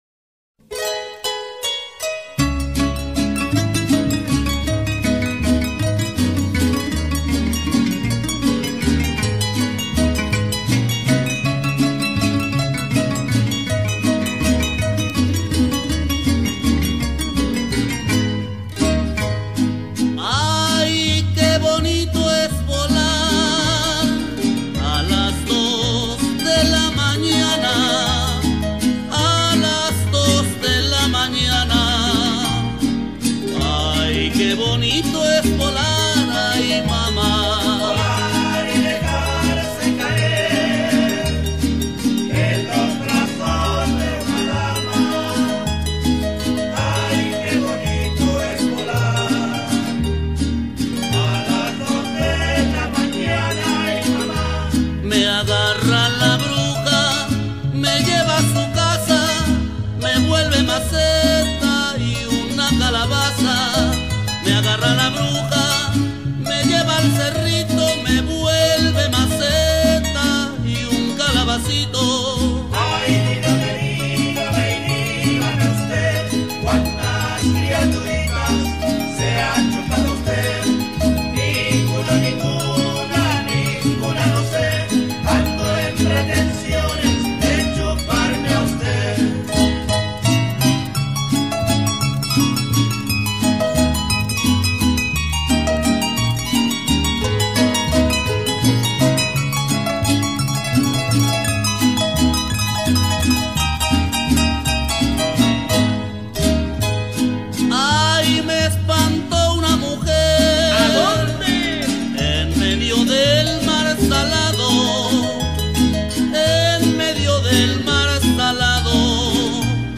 Veracruz trad.